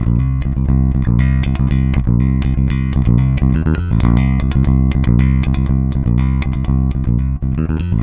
-JP THROB C.wav